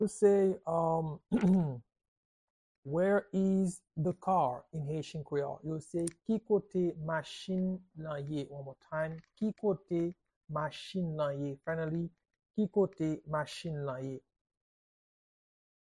Pronunciation and Transcript:
How-to-say-Where-is-the-car-in-Haitian-Creole-–Ki-kote-machin-lan-ye-pronunciation-by-a-Haitian-tutor.mp3